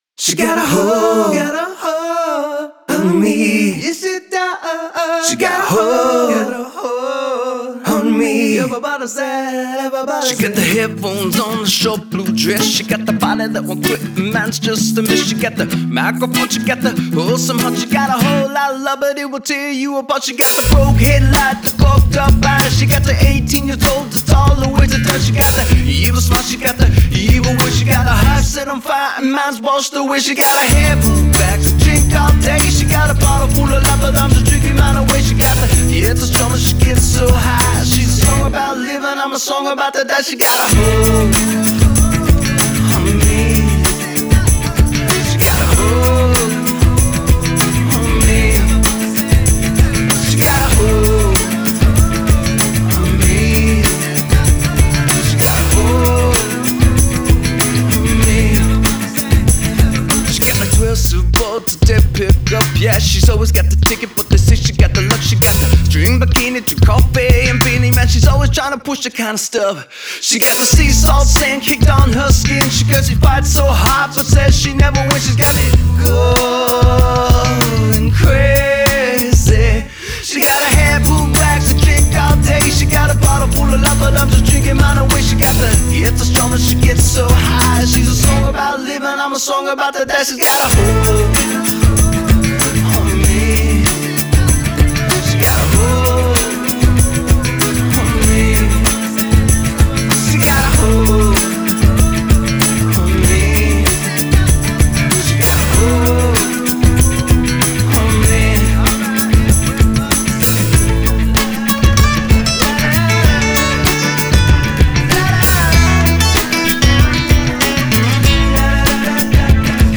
My biggest problem is usually the low end as I’m mixing almost exclusively on cans, so please let me know if it’s a big mess or not. Currently I’ve got a drum machine rolling in the back, but that will be replaced by live drums very soon.